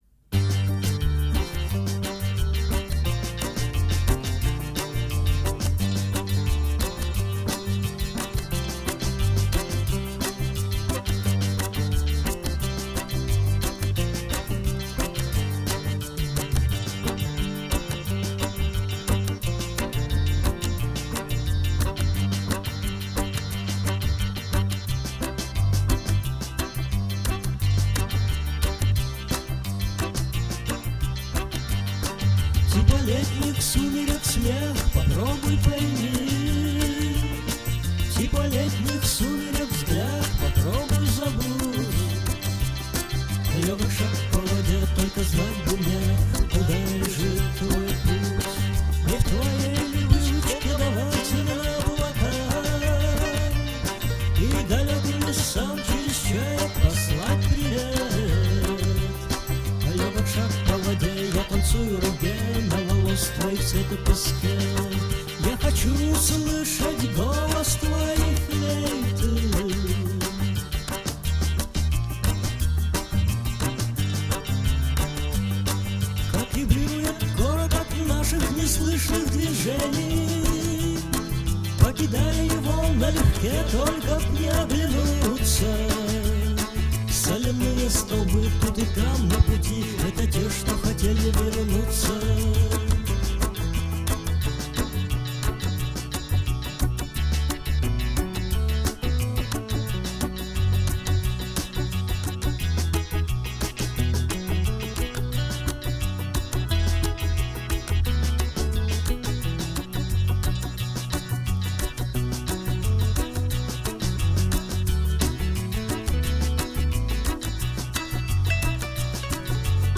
Арт-рок и авангард.